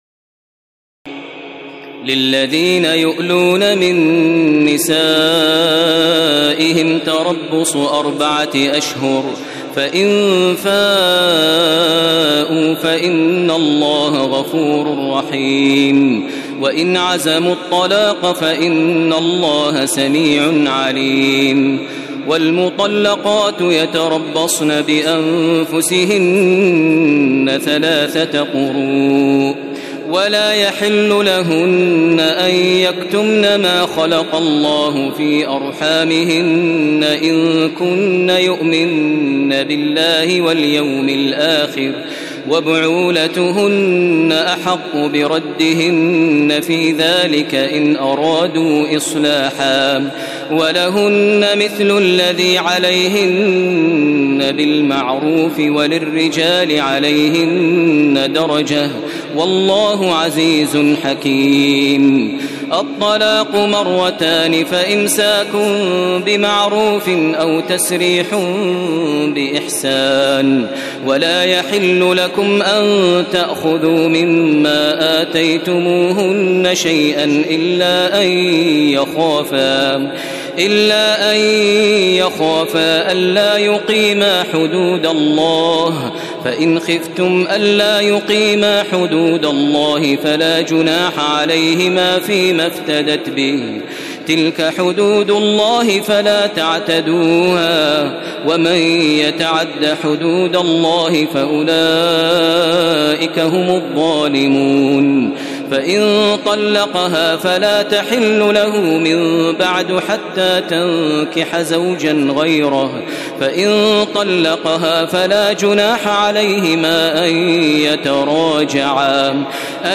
تراويح الليلة الثانية رمضان 1432هـ من سورة البقرة (226-271) Taraweeh 2 st night Ramadan 1432H from Surah Al-Baqara > تراويح الحرم المكي عام 1432 🕋 > التراويح - تلاوات الحرمين